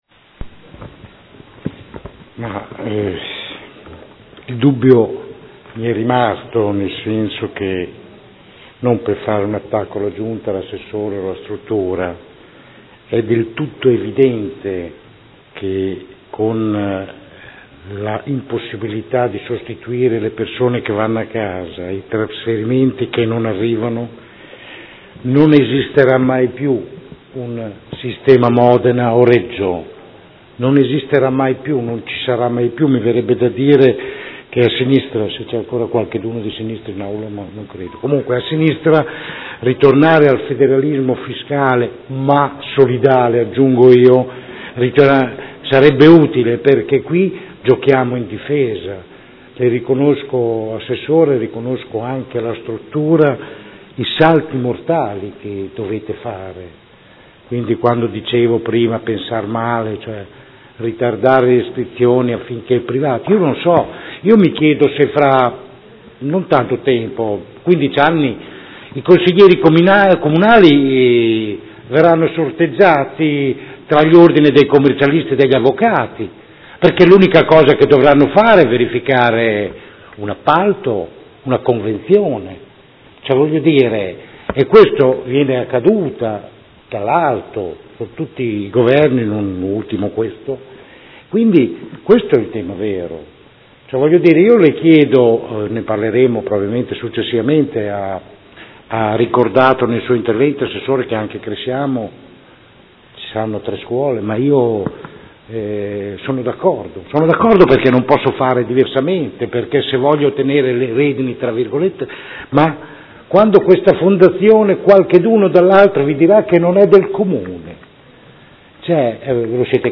Francesco Rocco — Sito Audio Consiglio Comunale
Seduta del 14/04/2015 Replica.